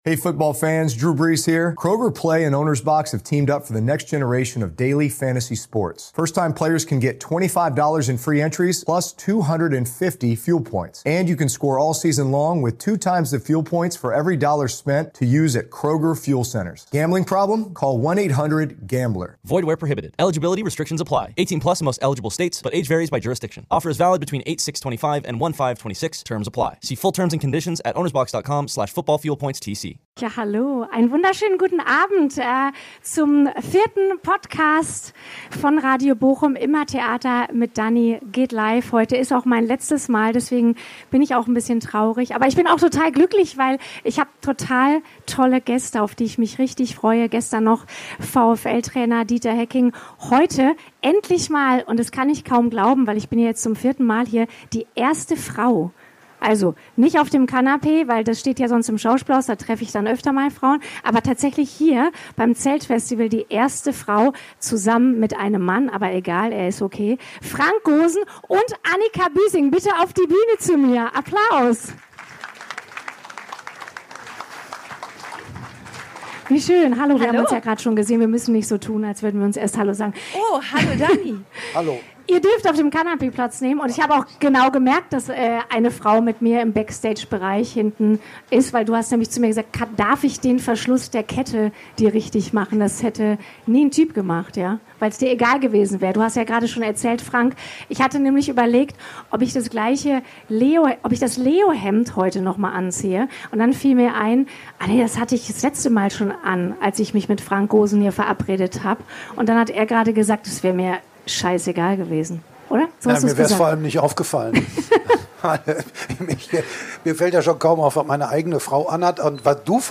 Also ein ganz normaler Plausch unter Menschen aus Bochum.